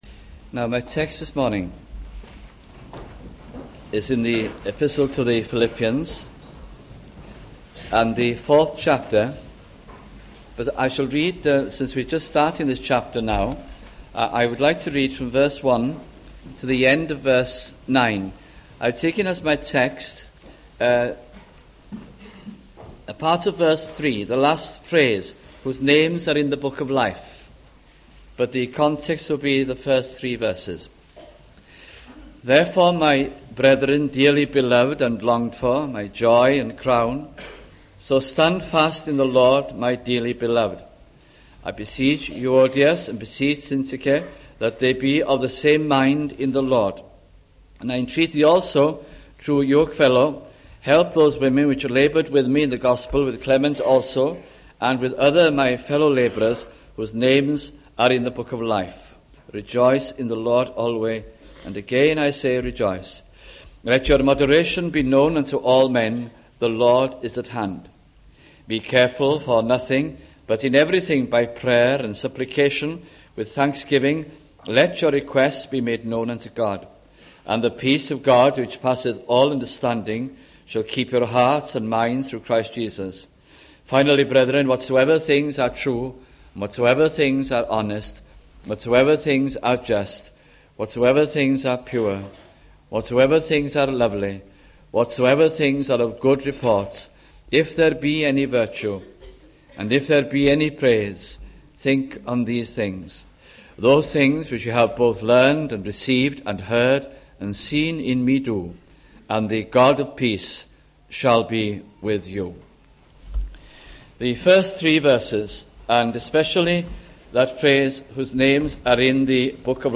» Philippians Series 1989-90 » sunday morning messages